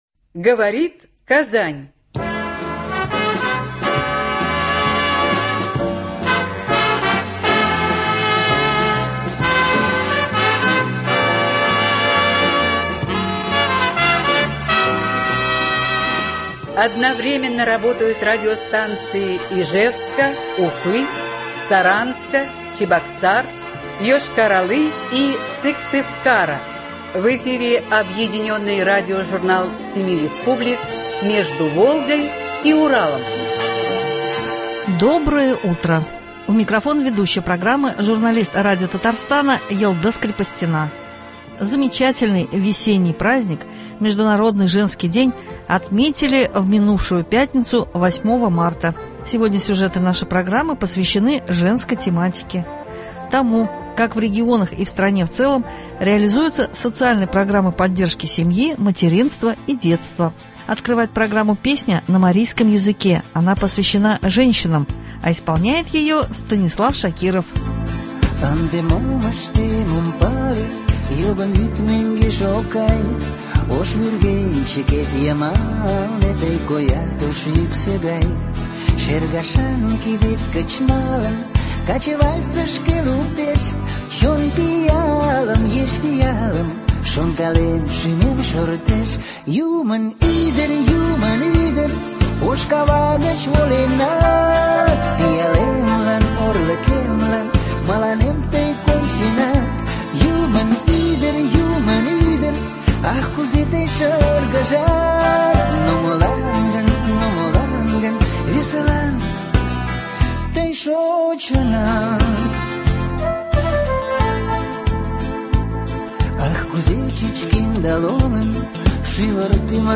Объединенный радиожурнал семи республик.